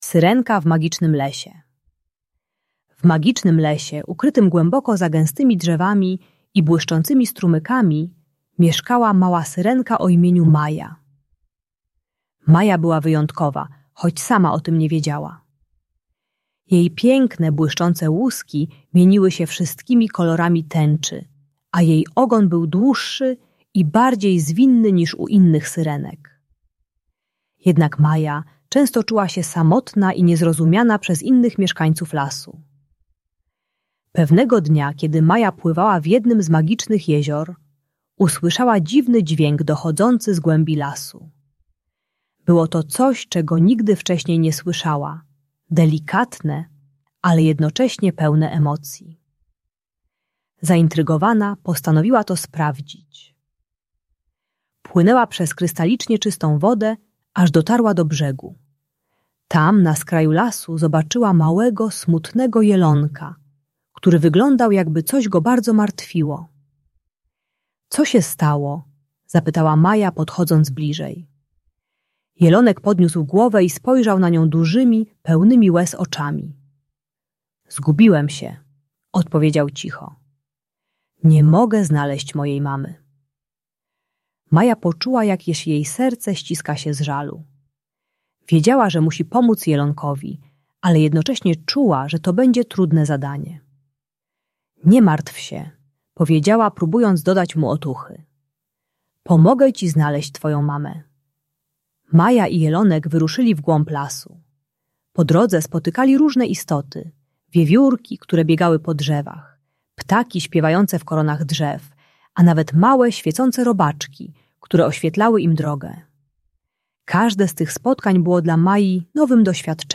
Syrenka w Magicznym Lesie - Bunt i wybuchy złości | Audiobajka